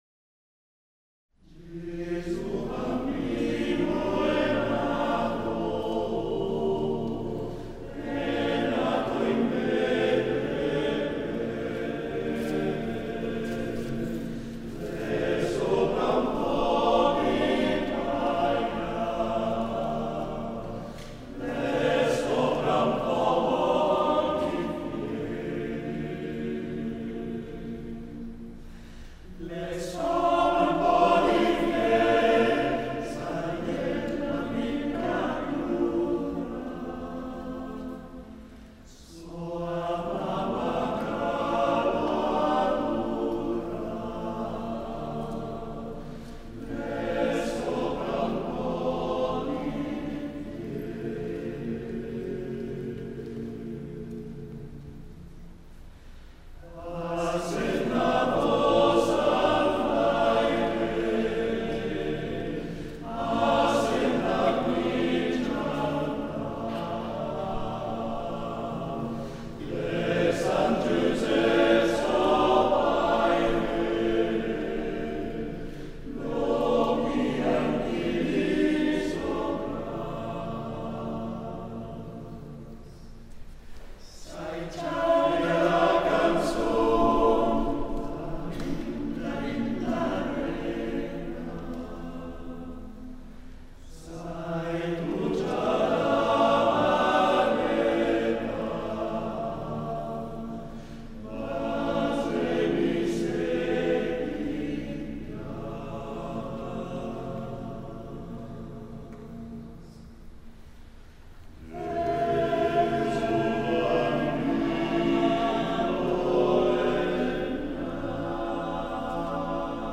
Gruppo Vocale Incanto